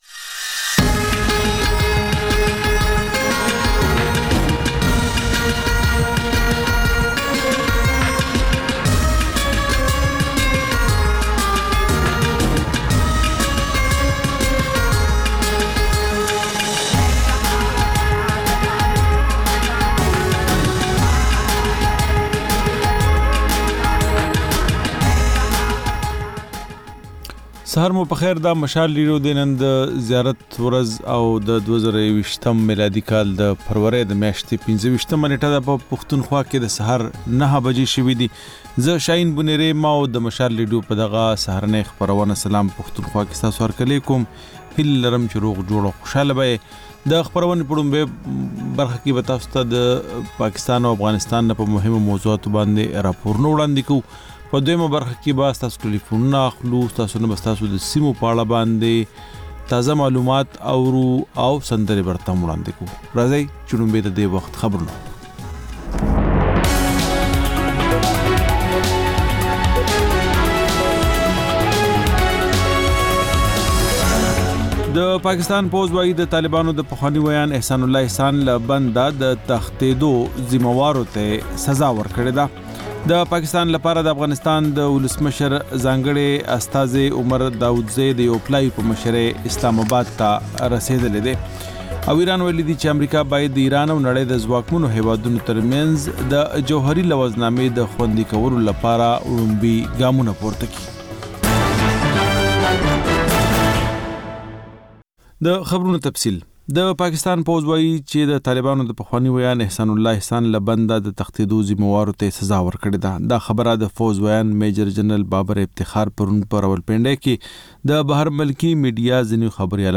دا د مشال راډیو لومړۍ خپرونه ده چې په کې تر خبرونو وروسته رپورټونه، له خبریالانو خبرونه او رپورټونه او سندرې در خپروو.